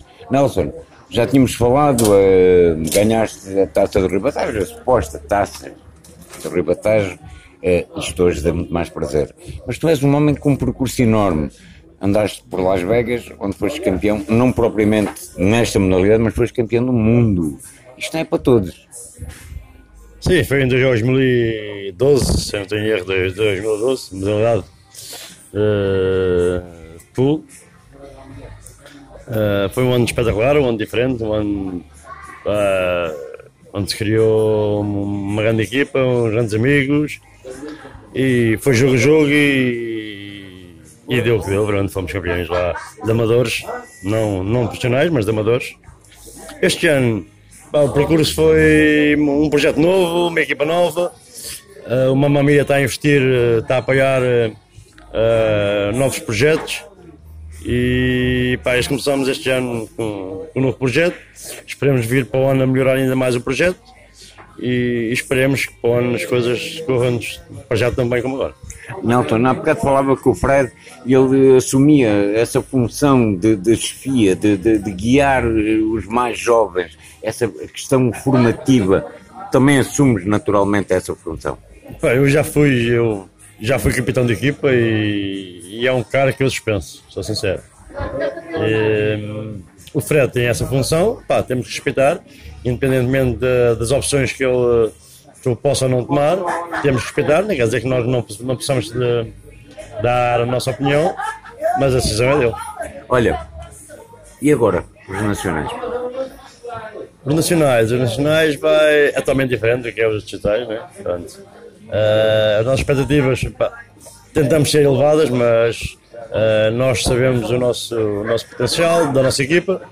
No final fomos ouvir alguns dos intervenientes na partida: